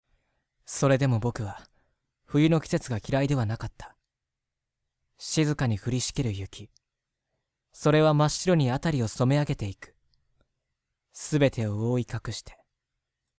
・真面目で勤勉、大人しい少年だった。
【サンプルボイス】